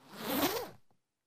Index of /server/sound/clothing_system/fastener